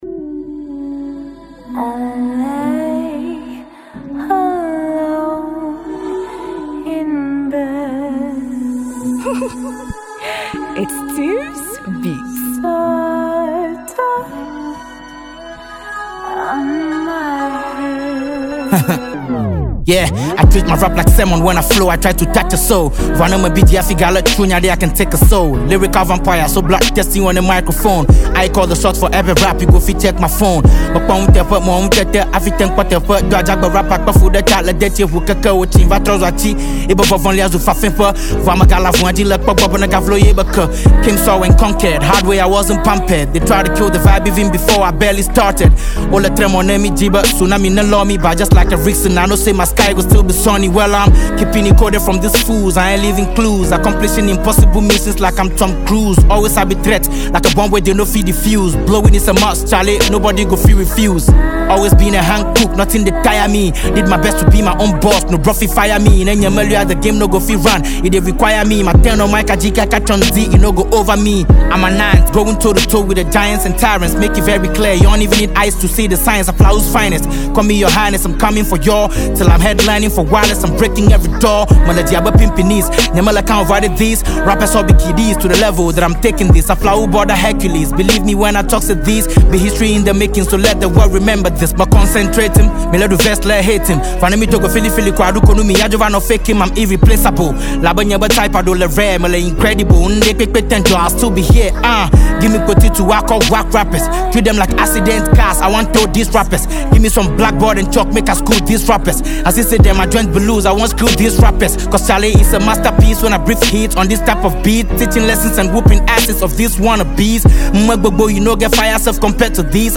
a Ghanaian rapper